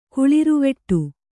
♪ kuḷiruveṭṭu